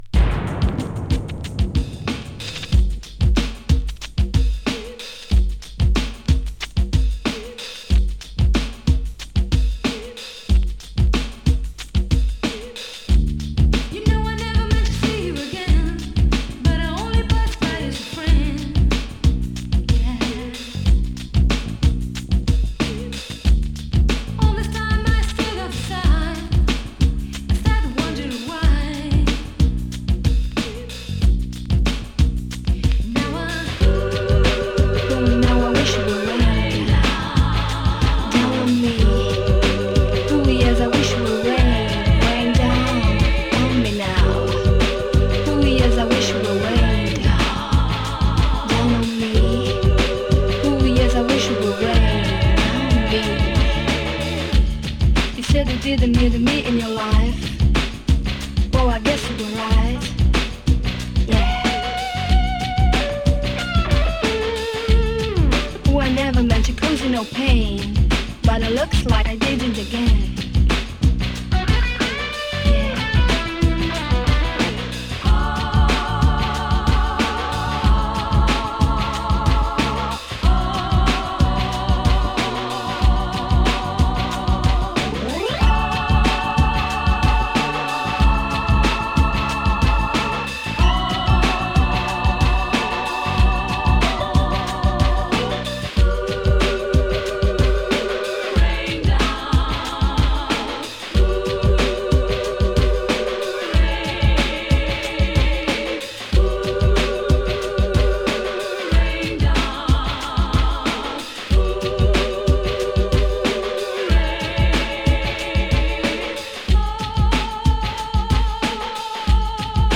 B面には呟くようなRAPが胸を締め付けるDub Versionを収録。
＊音の薄い部分で軽いチリパチ・ノイズ。
♪Dub Version (3.31)♪